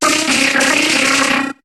Cri de Noadkoko dans Pokémon HOME.